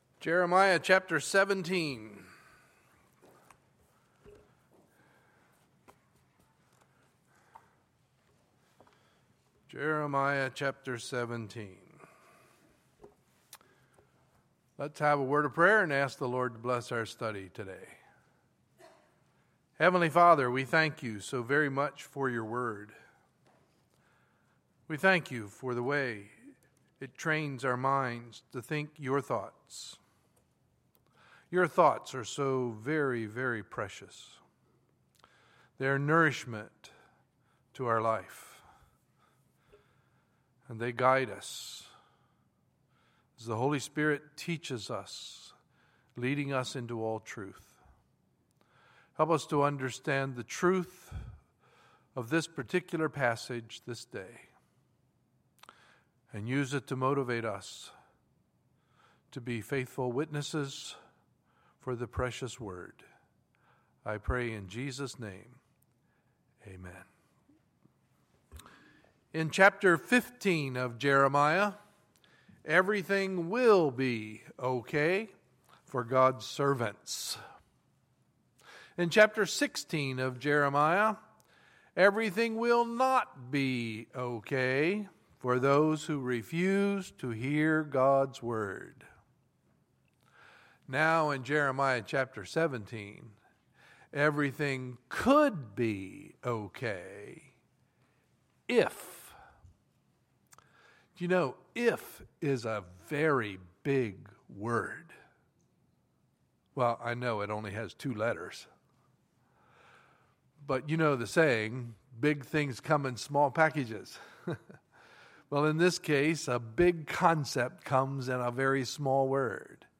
Sunday, May 31, 2015 – Sunday Morning Service